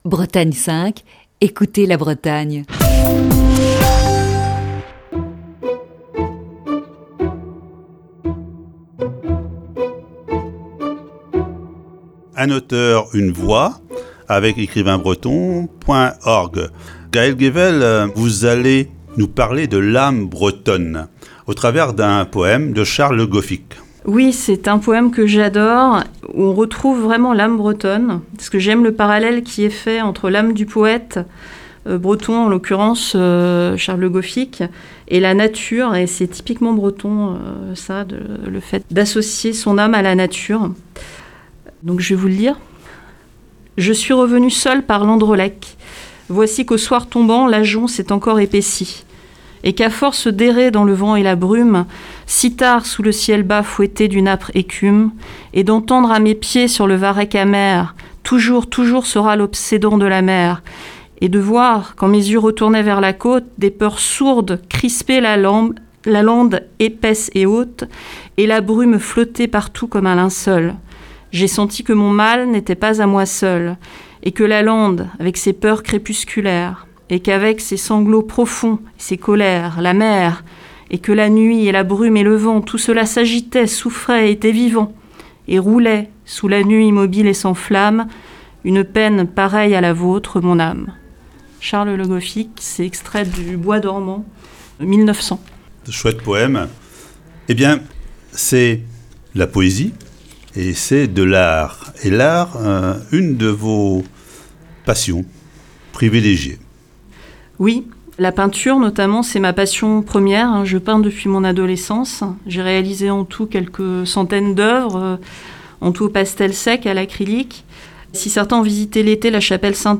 Voici ce mercredi la troisième partie de cette série d'entretiens.